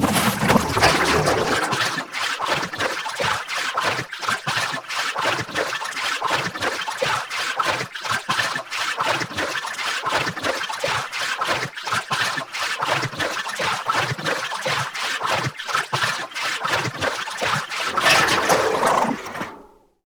bottle.wav